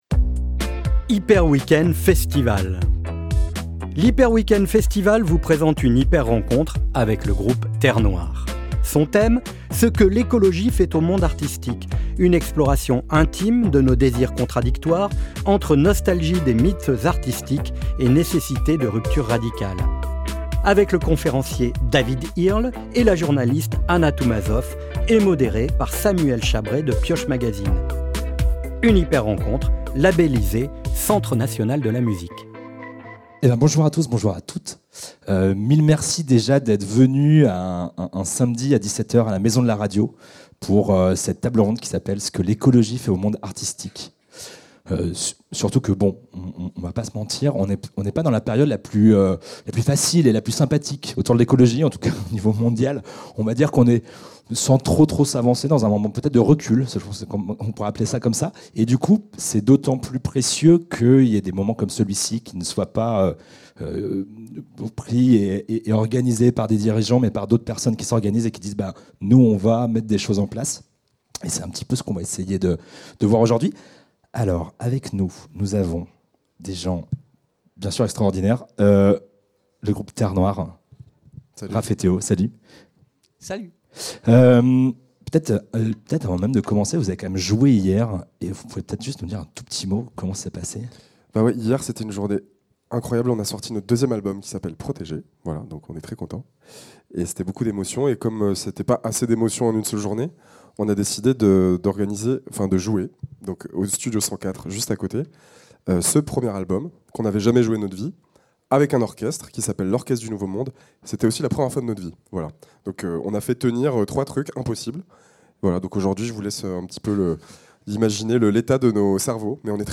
Rendez-vous les 24, 25 & 26 janvier 2025 à la Maison de la Radio et de la Musique pour l’Hyper Weekend.
Durée de la rencontre: 1h RE-ECOUTEZ LES AUTRES HYPER RENCONTRES (Bilal Hassani, Laurent Garnier) ICI